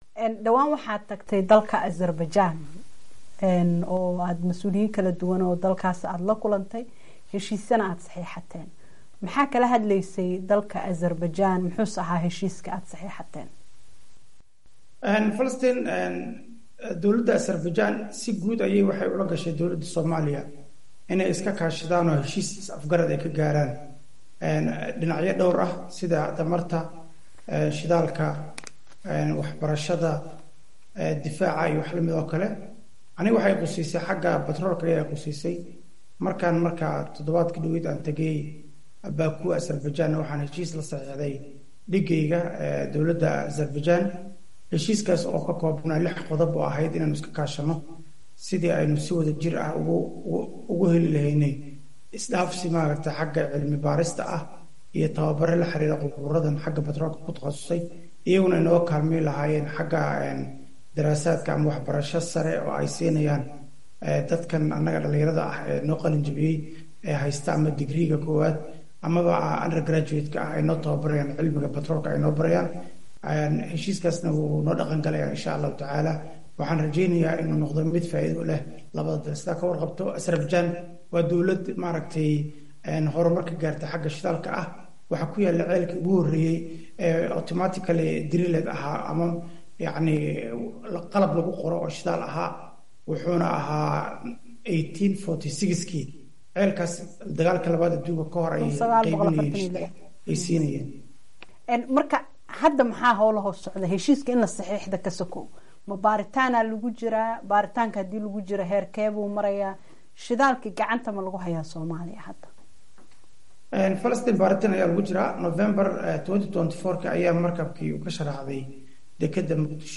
Wareysi: Maxaa ka cusub shidaal sahminta Soomaaliya?